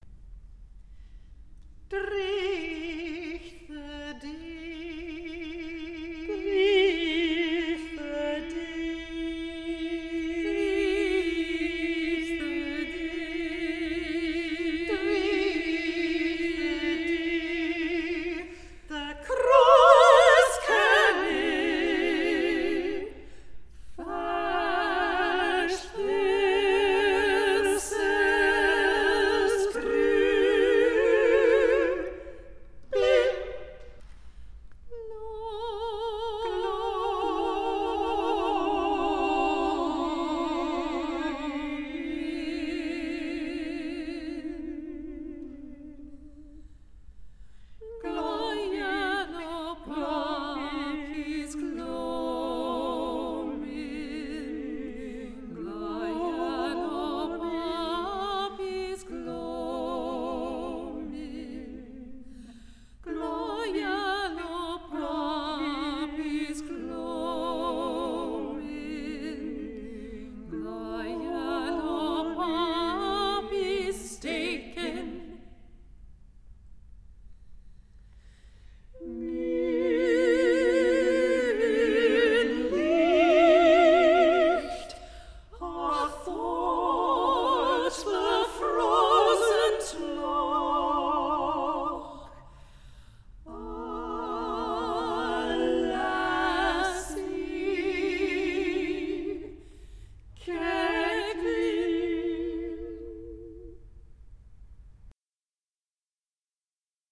female vocal trio
Recorded June 2014 – Studio recording